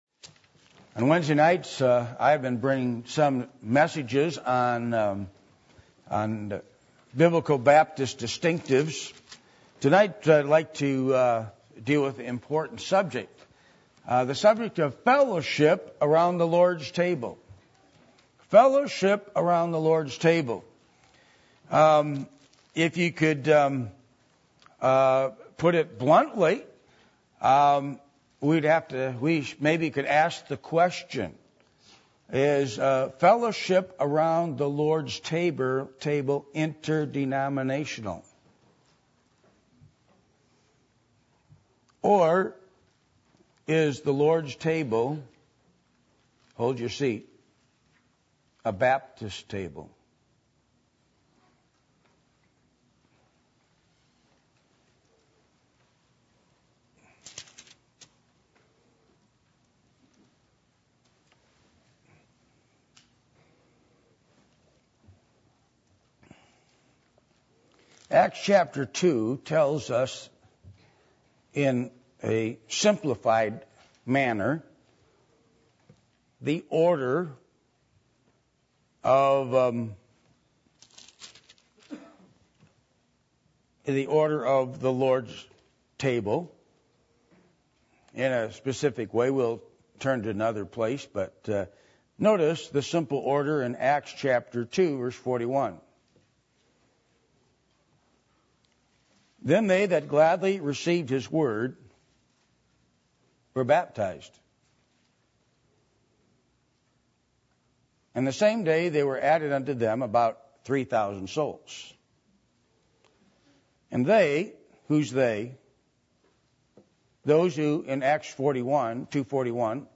Passage: Acts 2:41-42 Service Type: Midweek Meeting %todo_render% « Moral Confusion In The Time Of Judges Christian Home Series